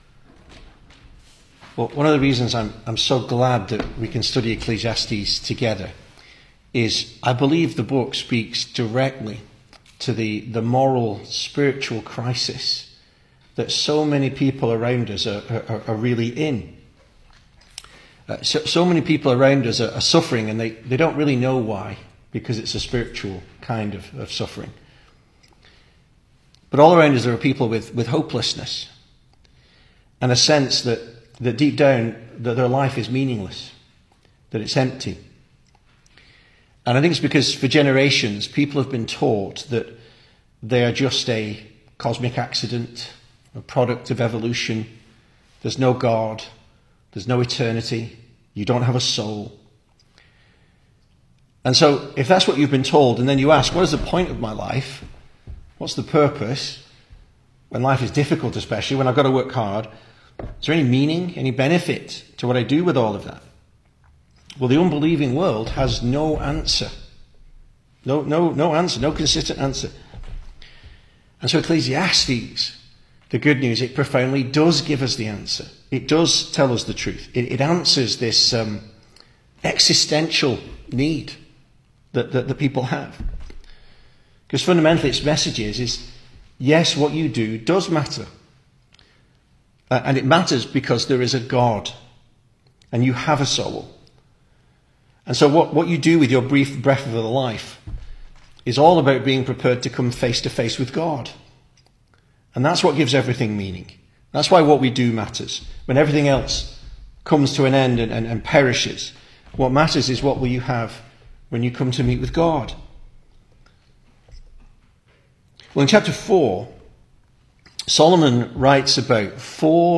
2025 Service Type: Weekday Evening Speaker